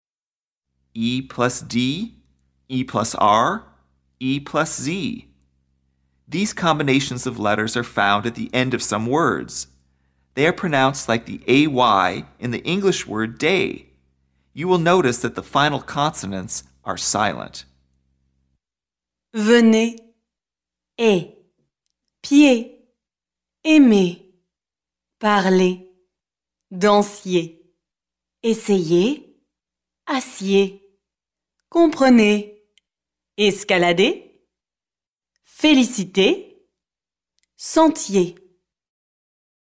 PRONONCIATION
ed, er, ez – These combinations of letters are found at the end of some words; they sound a little like the “ay” in the English word “day.” You will notice that the final consonants are silent.
Note: The word “et” meaning “and” also sounds a little like the “ay” in “day,” but it is more cut off.